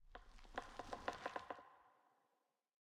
pale_hanging_moss8.ogg